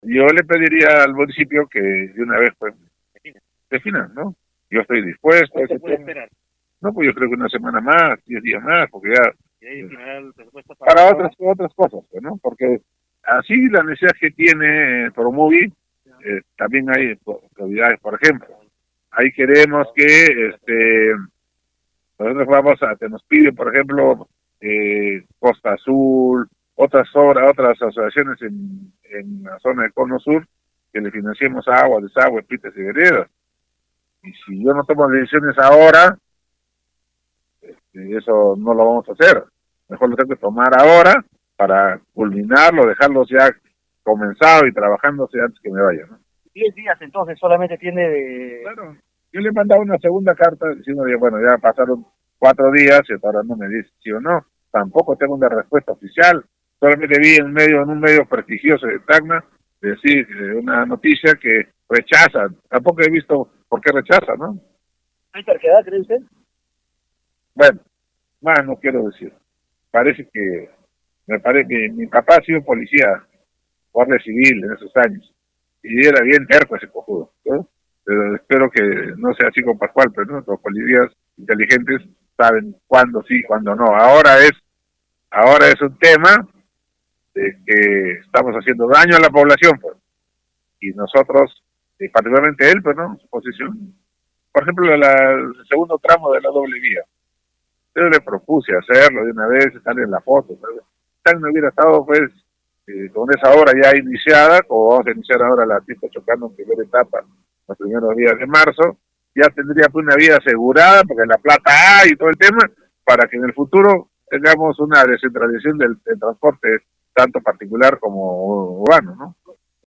Las declaraciones fueron brindadas durante una inspección a la carretera TA-628, en el sector Cerro Blanco, distrito de Calana.
LUIS-TORRES.mp3